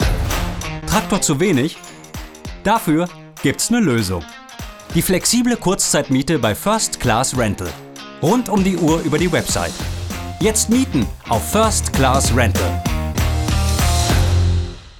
sehr variabel, dunkel, sonor, souverän
Mittel minus (25-45)
Commercial (Werbung)